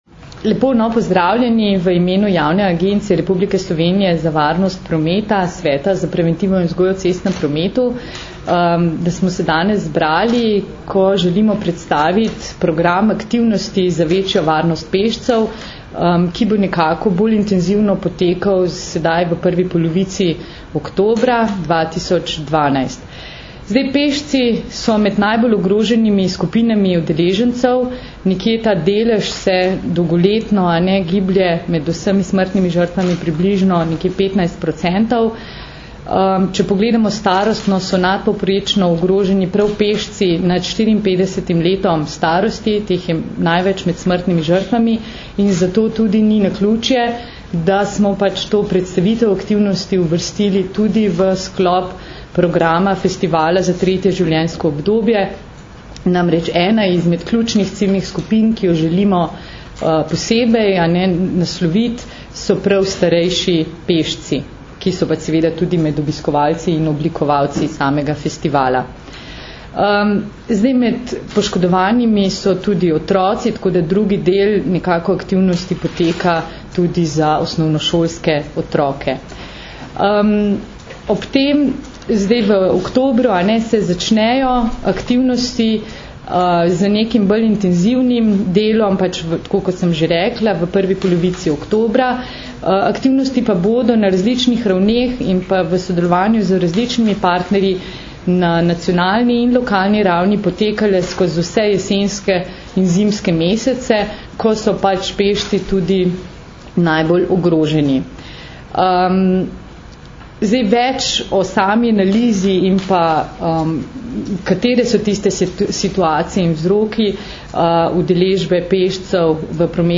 Na današnji novinarski konferenci, ki jo je organizirala Javna agencija RS za varnost prometa, smo predstavili akcijo za večjo varnost pešcev Bodi preViden, ki bo potekala med 1. in 14. oktobrom 2012.
Zvočni posnetek izjave